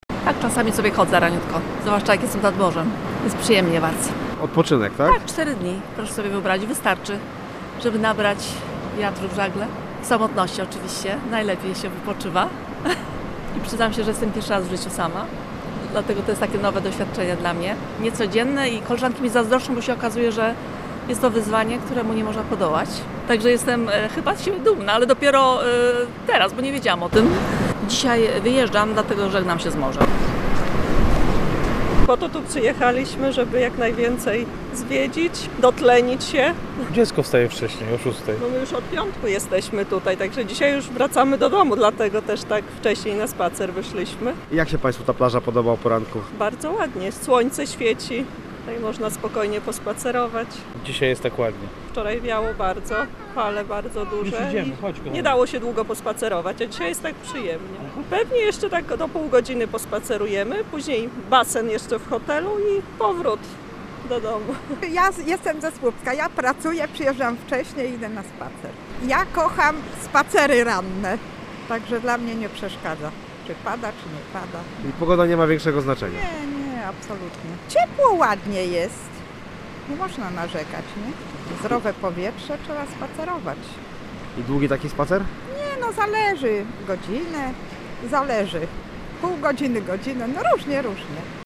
Z turystami korzystającymi ze słonecznego poranka rozmawiał nasz reporter.